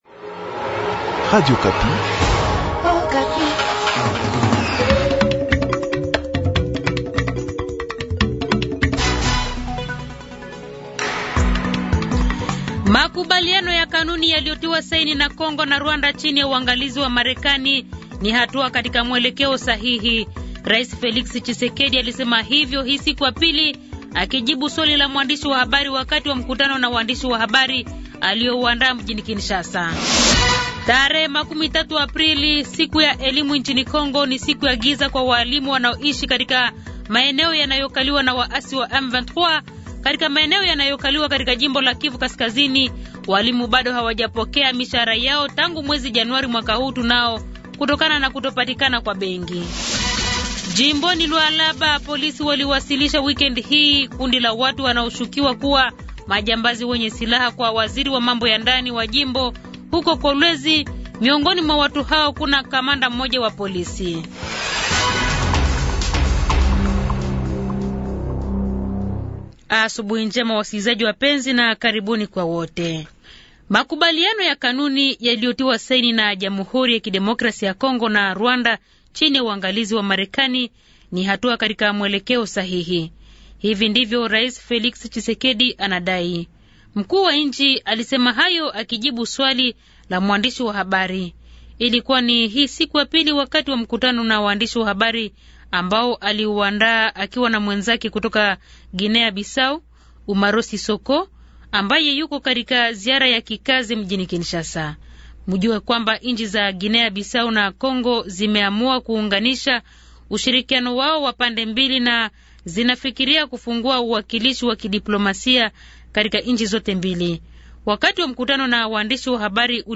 Journal Matin
Habari z'asubuhi 30 Aprili, 2025